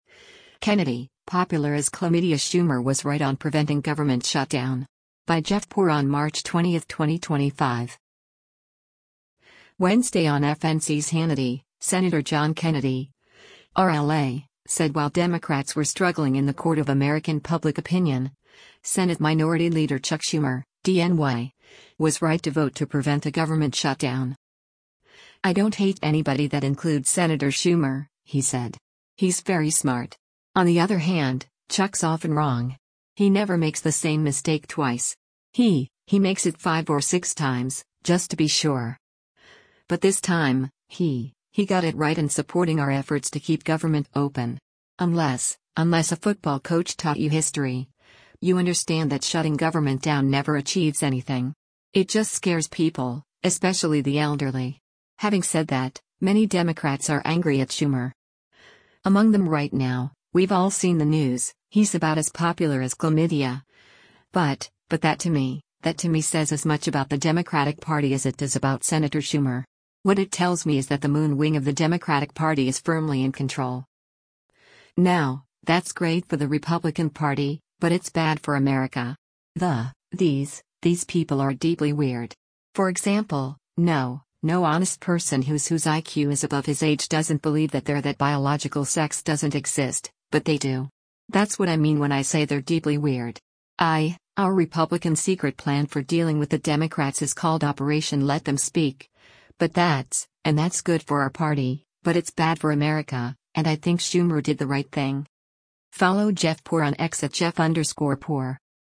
Wednesday on FNC’s “Hannity,” Sen. John Kennedy (R-LA) said while Democrats were struggling in the court of American public opinion, Senate Minority Leader Chuck Schumer (D-NY) was right to vote to prevent a government shutdown.